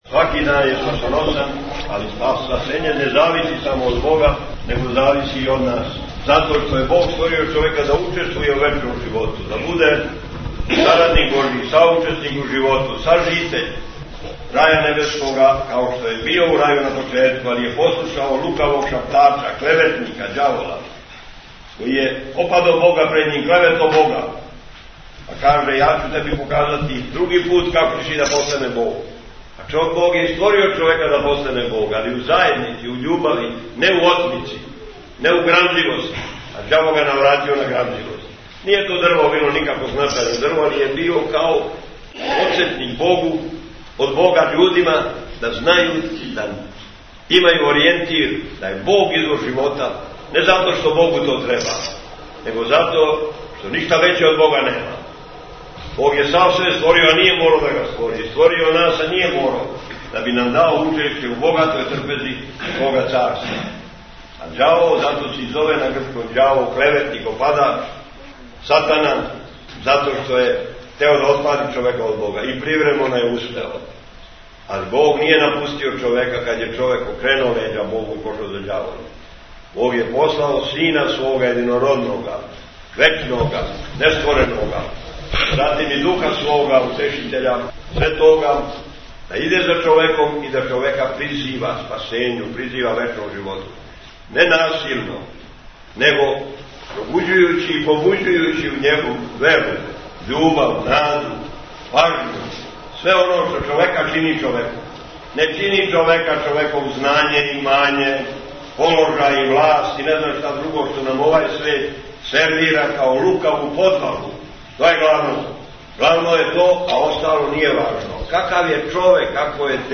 Епископ Атанасије служио у новообновљеном храму у Белом Пољу код Пећи, 14. март 2010 | Радио Светигора
Tagged: Бесједе Наслов: Episkop Atanasije (Jevtic) Албум: Besjede Година: 2010 Величина: 16:03 минута (2.76 МБ) Формат: MP3 Mono 22kHz 24Kbps (CBR) Његово Преосвештенство Епископ Атанасије служио је 14. марта 2010. године Свету Архијерејску Литургију у храму Рођења Пресвете Богородице у српском повратничком селу Бело Поље код Пећи уз саслужење Викарног Епископа Теодосија и четири свештенослужитеља.